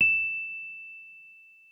piano-sounds-dev
Rhodes_MK1
e6.mp3